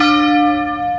A chaque déplacement de la cloche correspondra un son de cloche.
dong13.mp3